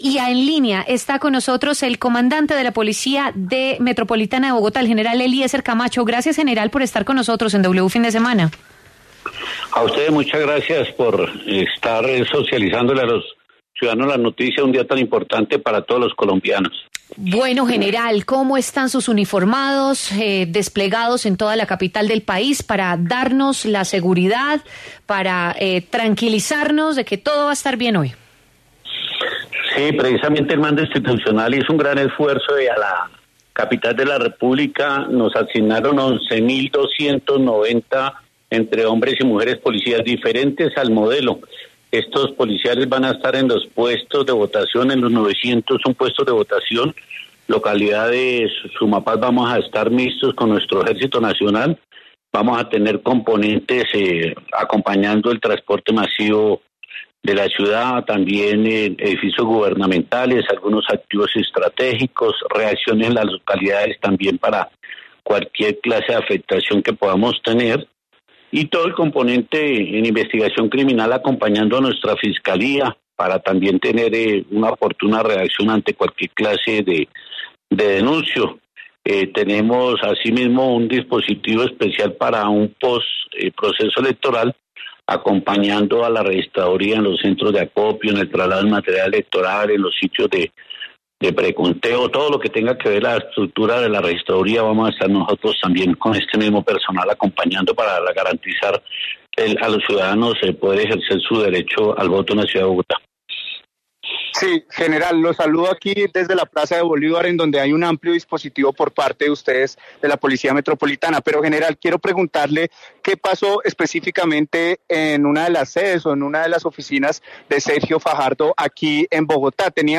En diálogo con W Fin de Semana, el mayor General de Policía Nacional, Eliecer Camacho anunció que por el momento solo se tiene el reporte del hurto de un dispositivo, sin embargo, se realizan las investigaciones correspondientes.